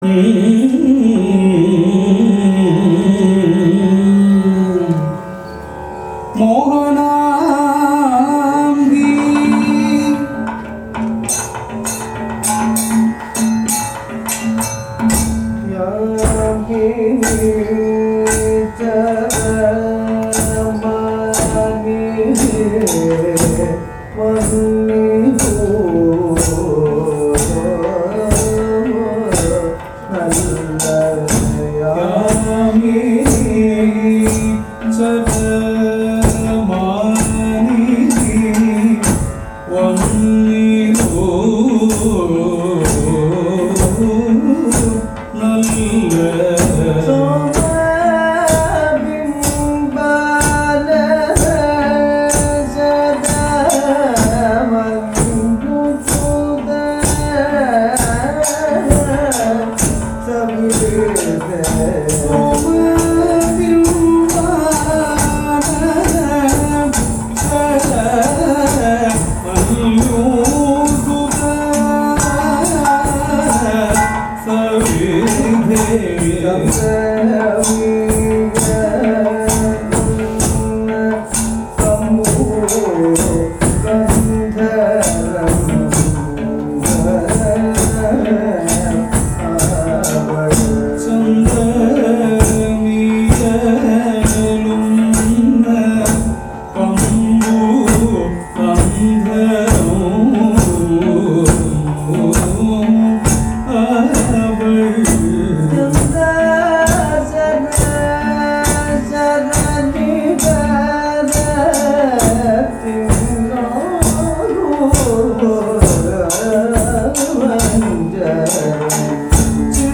◎南インドの伝統舞踊カタカリ
左が王子様、右が悪魔で美人に化けていますが、王子に見破られ胸と鼻を斬られて殺されるという芝居で、パントマイムの最後の所でギャーという叫び声を上げるところが面白いところです。
◎　カタカリの音楽